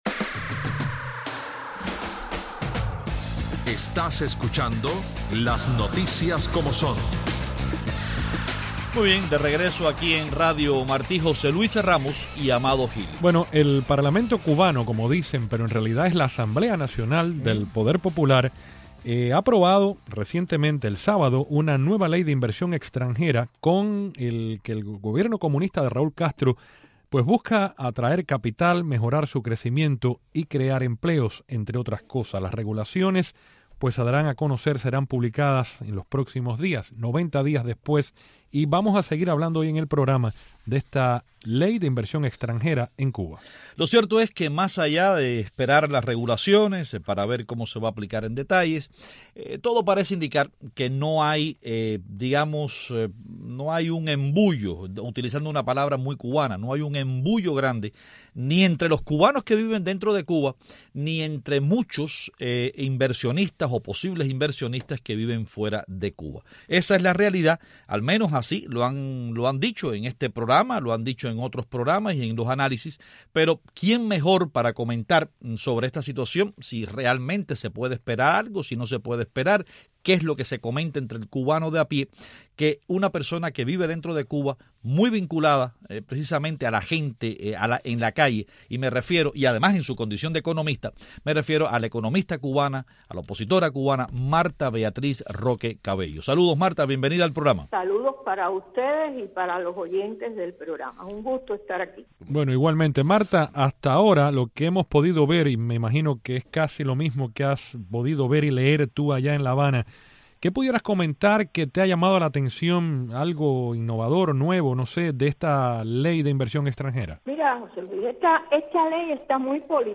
Hoy hablamos con la opositora y economista independiente, Martha Beatriz Roque Cabello, sobre la nueva ley de inversión extranjera aprobada por la Asamblea nacional cubana. Ella participa desde La Habana. También, en Caracas, funcionarios de la Policía Nacional Bolivariana impidieron el avance de la diputada, María Corina Machado, hacia la Asamblea Nacional, reprimiendo la marcha con gas lacrimógeno y perdigones.